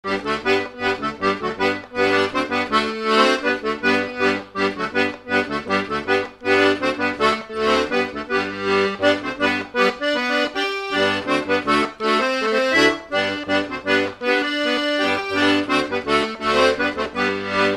Mazurka
Résumé instrumental
danse : mazurka
Pièce musicale inédite